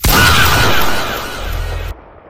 faaaa w pump shotgun Meme Sound Effect
faaaa w pump shotgun.mp3